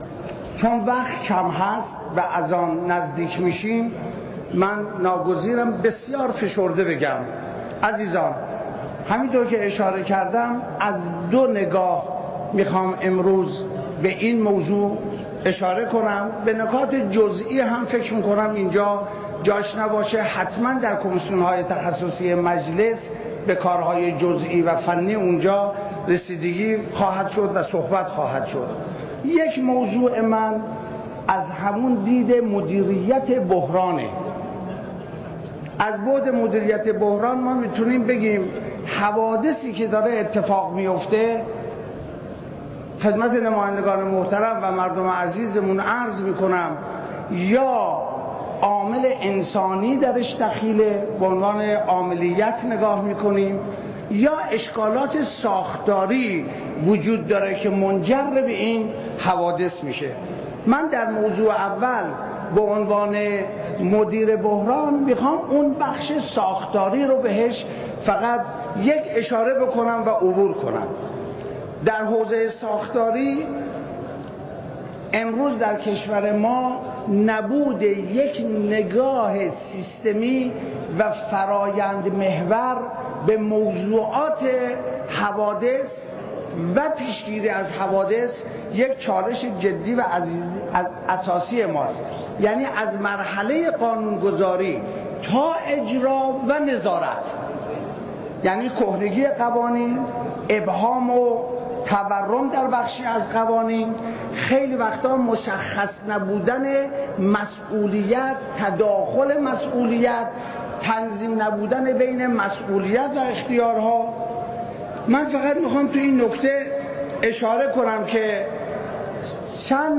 اظهارات قالیباف در صحنی علنی مجلس درباره حادثه پلاسکو صوت - تسنیم
به گزارش خبرنگار اجتماعی خبرگزاری تسنیم، محمدباقر قالیباف شهردار تهران صبح امروز برای ارائه گزارش درخصوص حادثه ریزش ساختمان پلاسکو در جلسه علنی مجلس شورای اسلامی حضور یافت.
در زیر، فایل صوتی گزارش‌ ارائه‌شده توسط قالیباف را می‌شنوید.